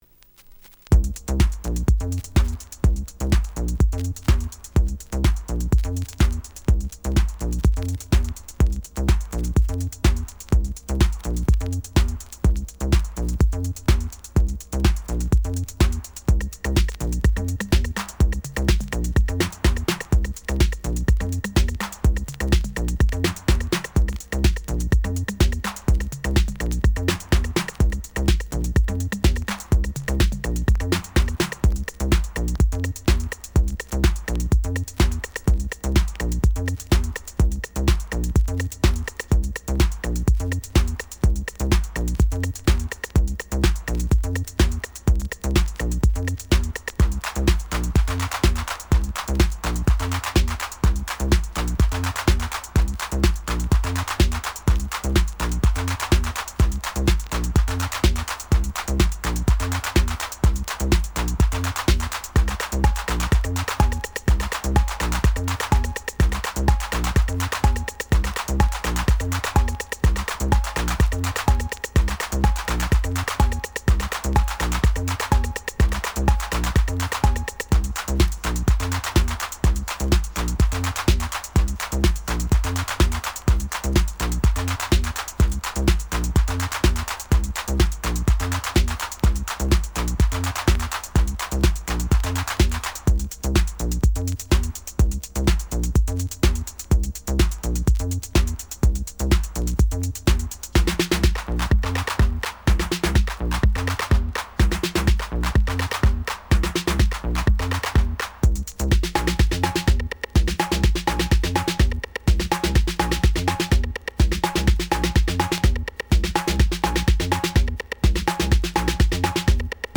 Genre: Classic House.